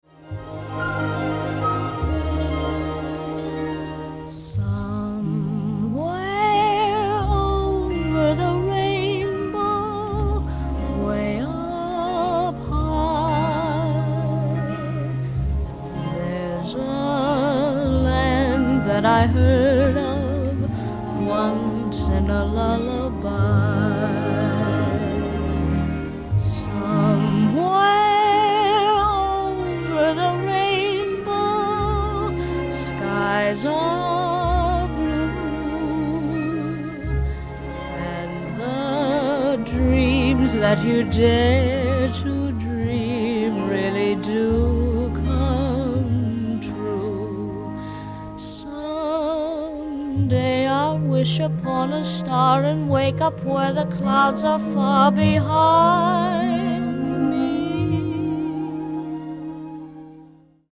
* Stereo Debut